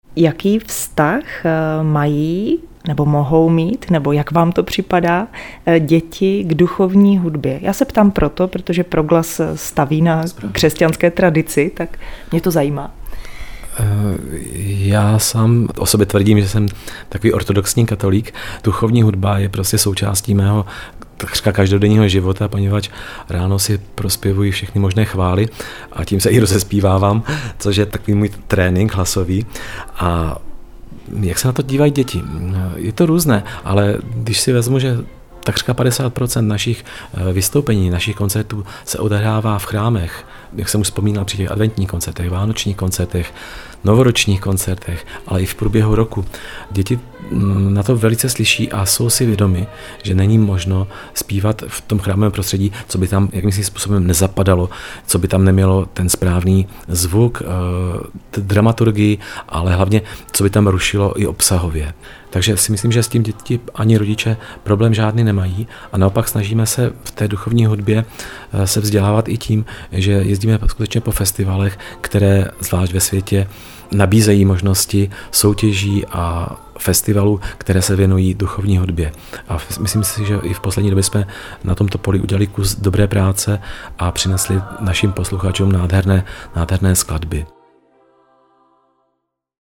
Audiopozvánka